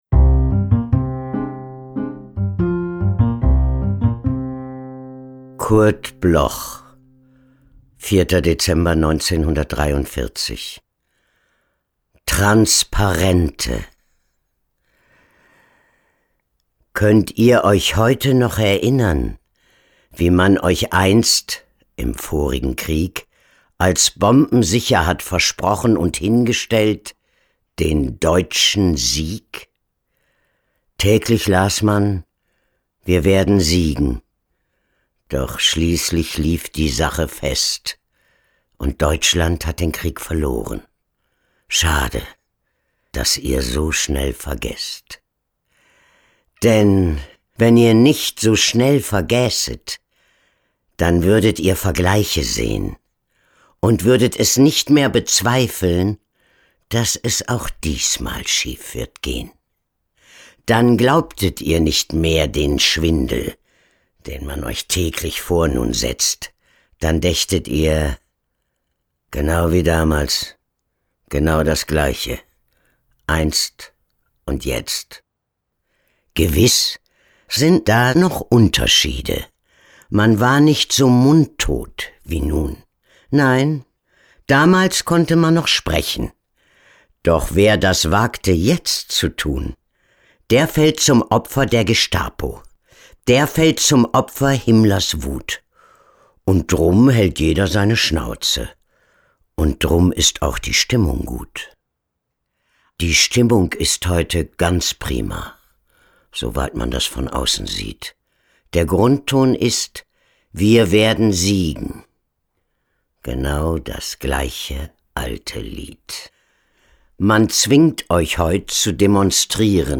vorgetragen von Mechthild Großmann
Mechthild-Grossmann-TRANSPARENTE-mit-Musik.m4a